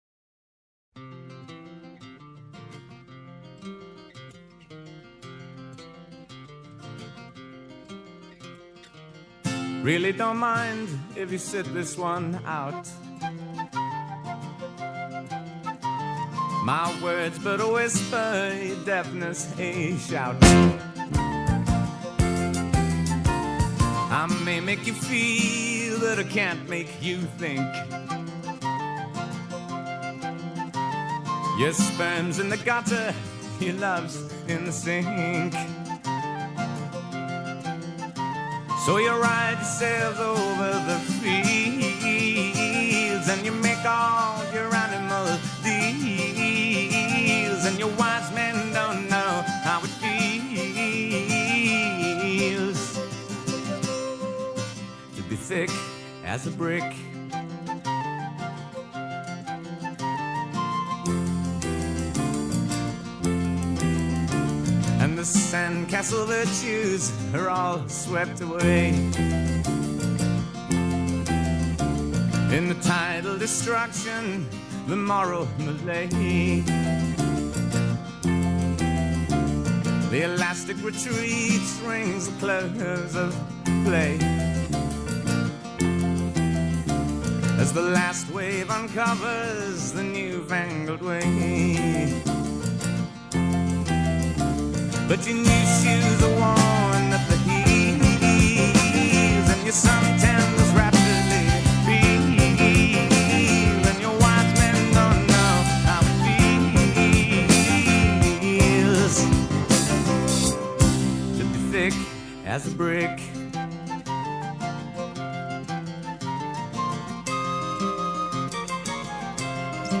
با جداسازی بهتر ابزارها و دینامیک بالاتر